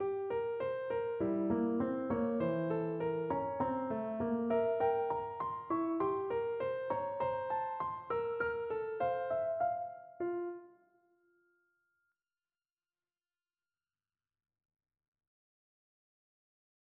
베토벤 피아노 소나타 A장조, 작품번호 101번의 카논